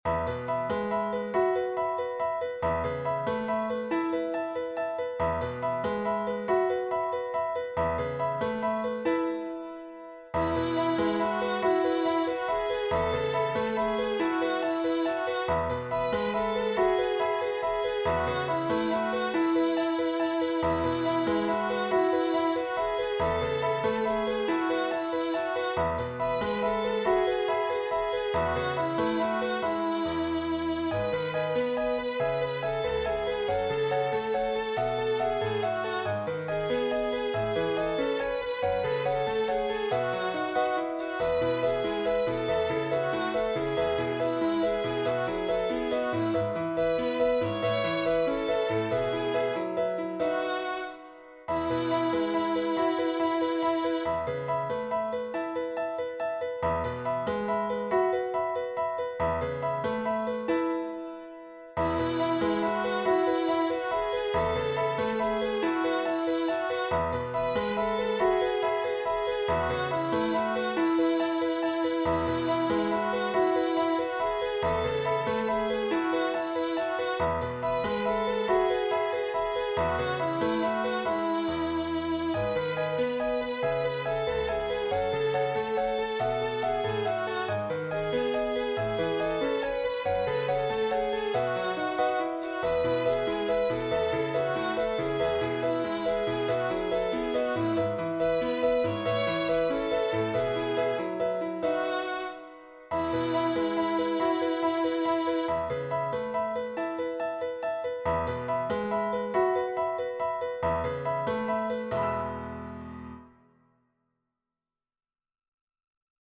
piano arrangement